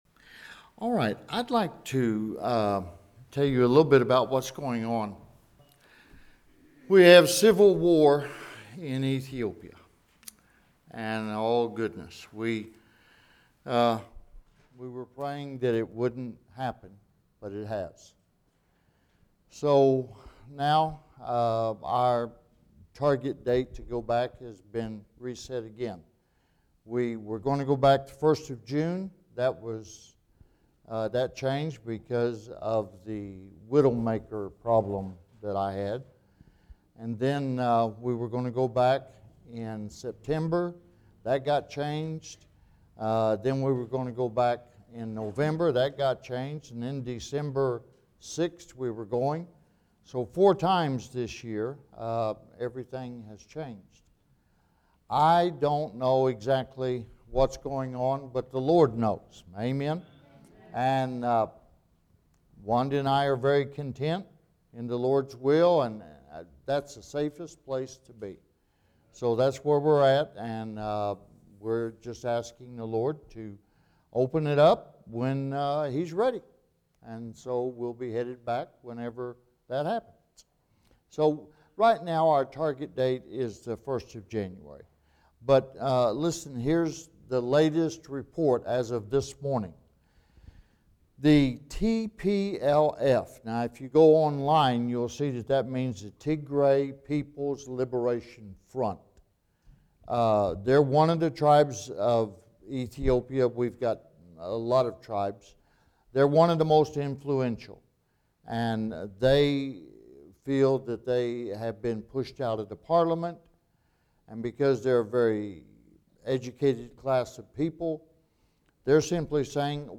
This sermon from Daniel chapter 12 challenges believers with the importance and primacy of soul-winning.